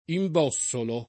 imbossolo [ i mb 0SS olo ]